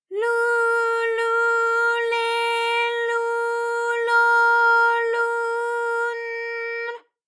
ALYS-DB-001-JPN - First Japanese UTAU vocal library of ALYS.
lu_lu_le_lu_lo_lu_n_l.wav